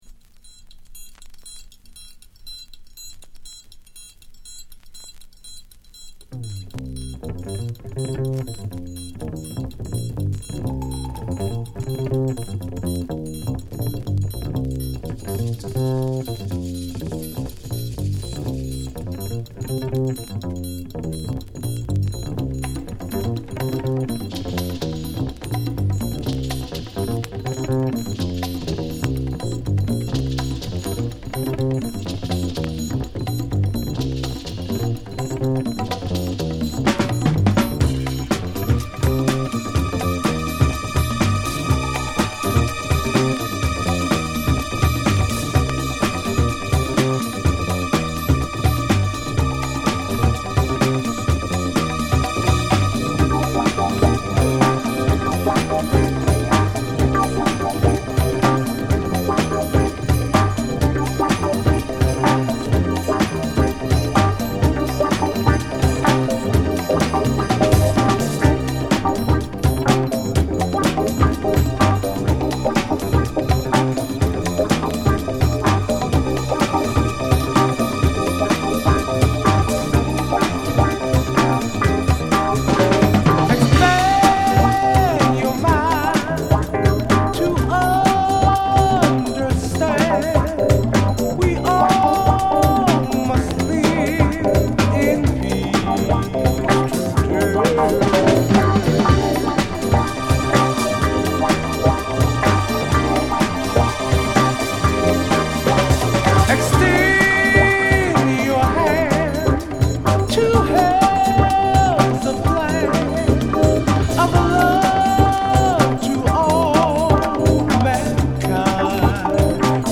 *試聴は別コピーからの録音です。